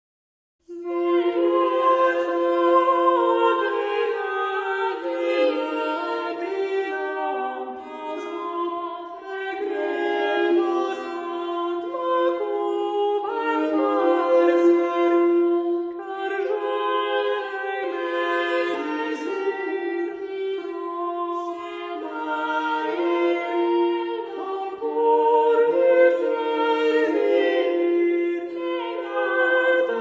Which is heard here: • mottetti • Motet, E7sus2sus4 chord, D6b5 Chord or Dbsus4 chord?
• mottetti • Motet